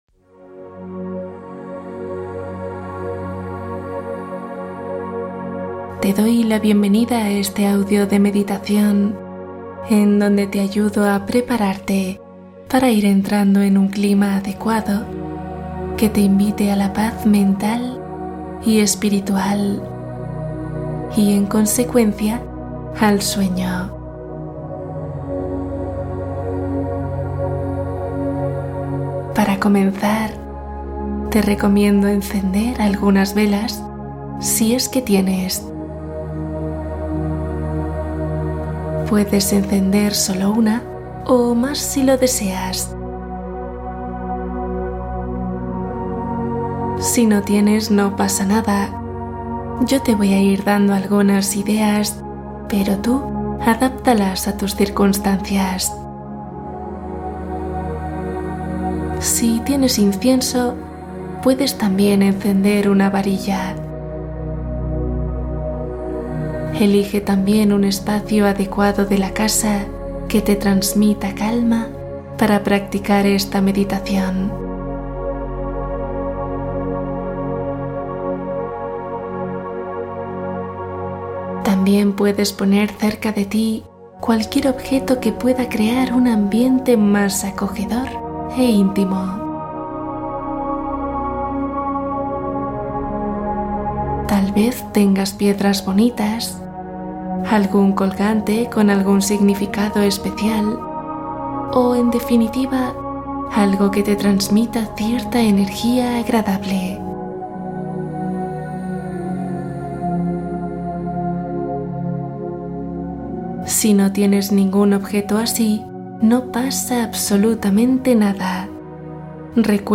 Calma tu ansiedad Meditación con cuento para dormir profundo